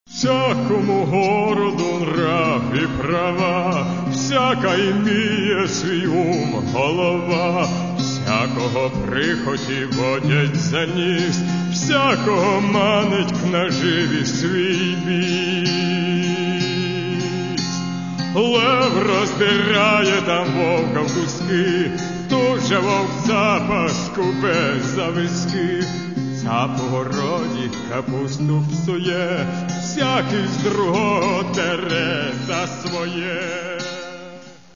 Каталог -> Народная -> Бандура, кобза